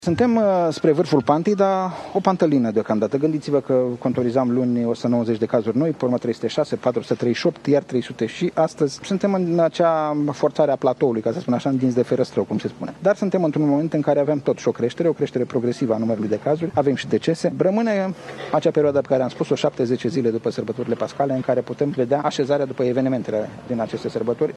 Ministrul Sănătăţii a declarat joi, la Deva, că România se apropie de un vârf al epidemiei de nou coronavirus.